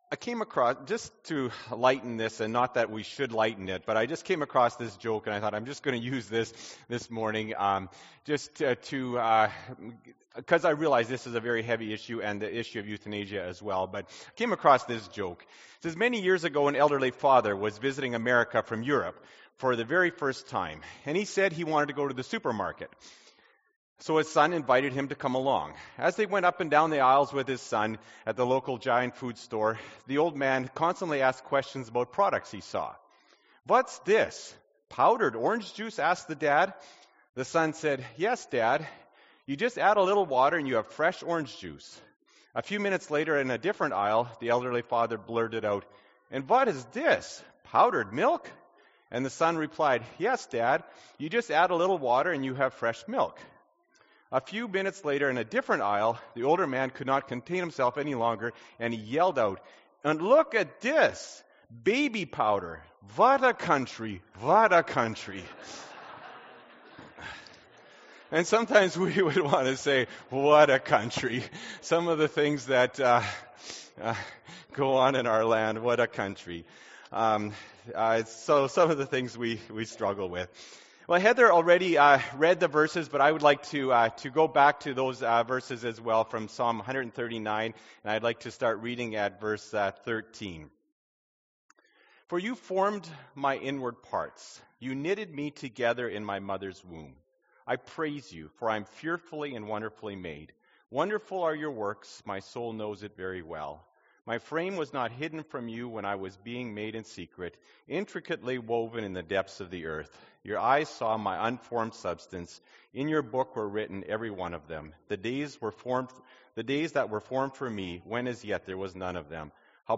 Feb. 7, 2016 – Sermon – Glencross Mennonite Church